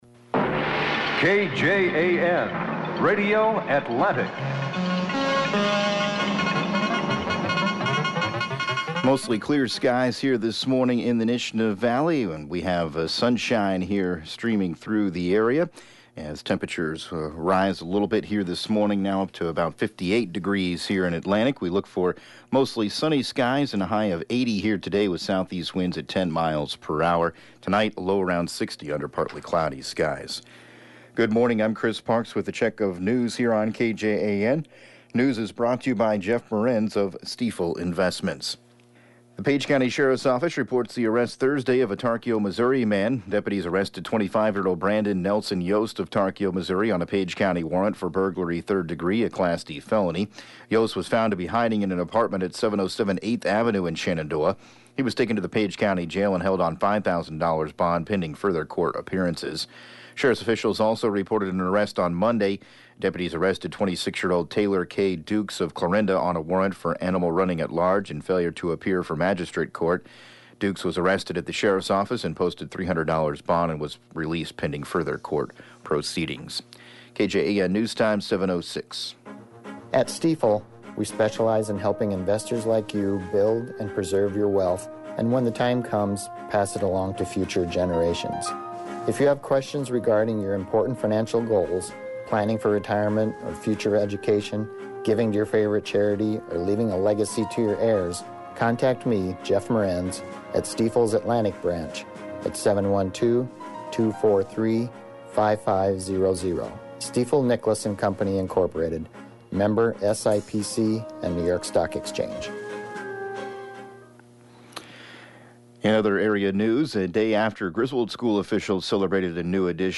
7AM Newscast 08/23/2019